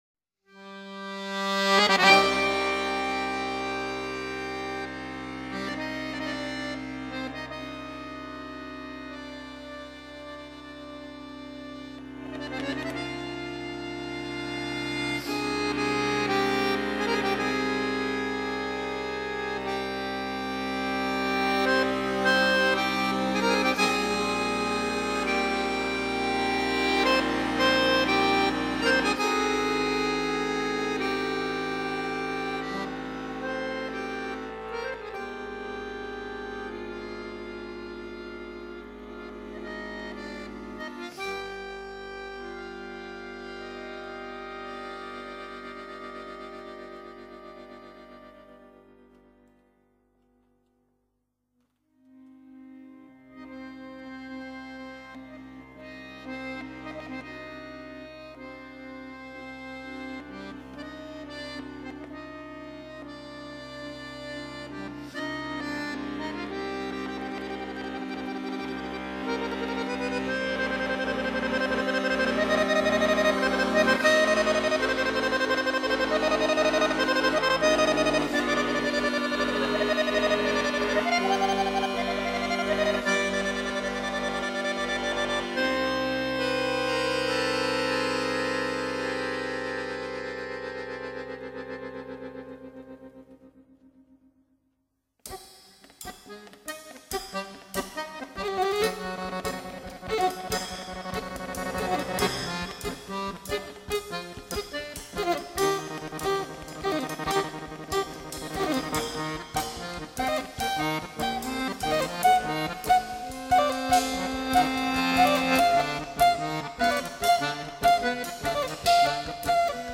zanfona
bajo
bateería